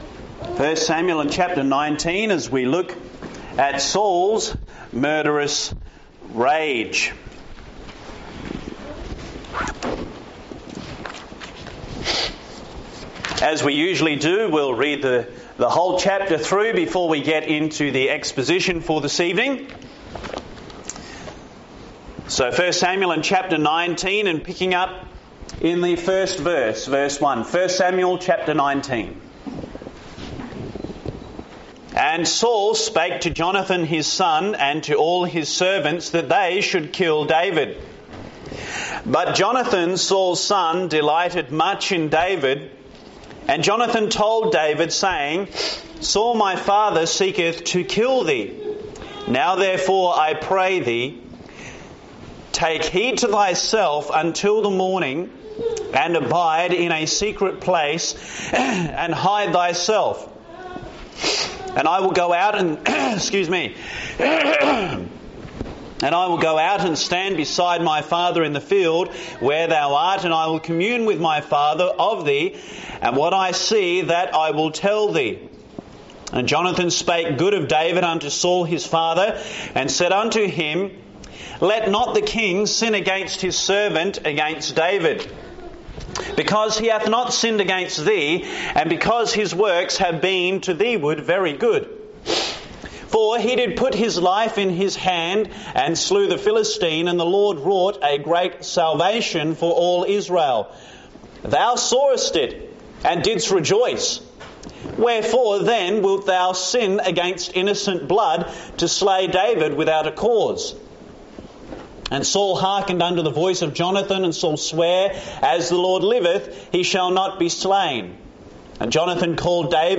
An exposition of 1 Samuel 19 showing Saul’s murderous rage, David’s escape, and the destructive spiral of sin when a heart refuses repentance.